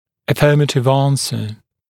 [ə’fɜːmətɪv ‘ɑːnsə][э’фё:мэтив ‘а:нсэ]утвердительный ответ